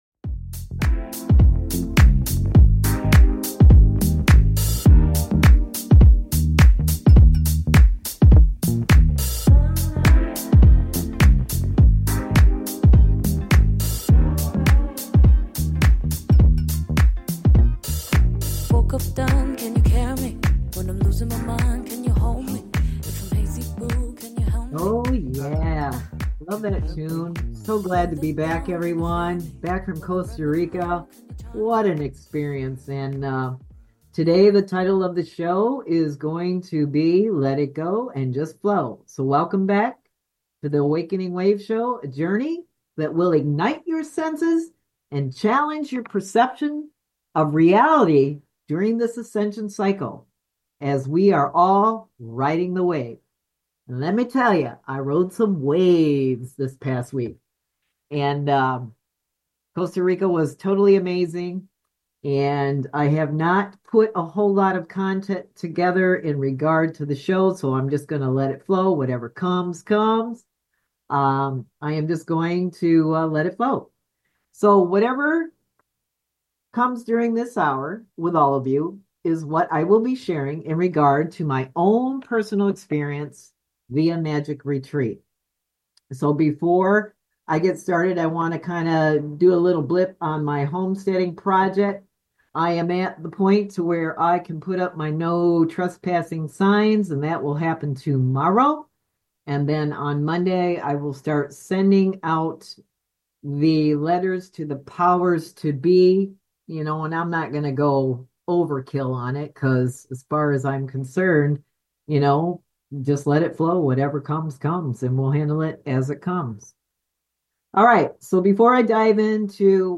The Awakening Wave Show is a fortnightly talk show exploring spiritual awakening and ascension, focusing on integrating spirituality into all aspects of life.